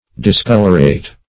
Discolorate \Dis*col"or*ate\